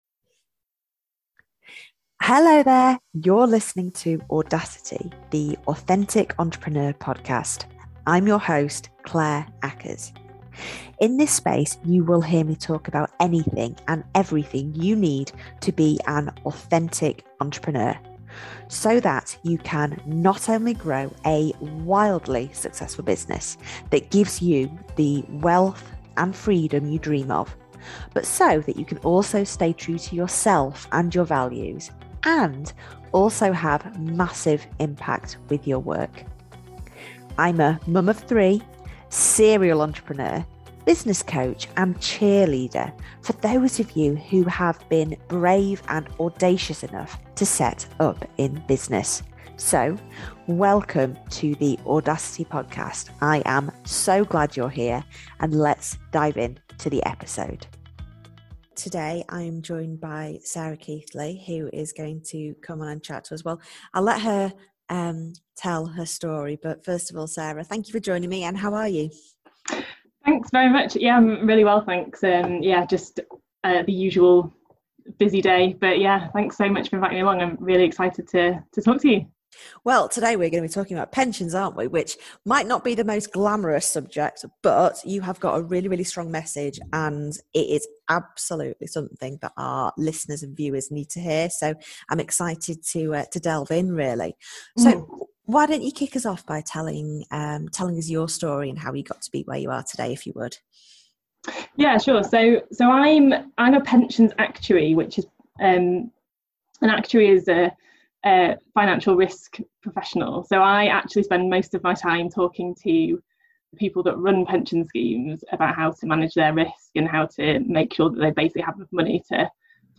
The Pensions Conversation - Interview